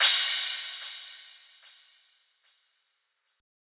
plugg crash.wav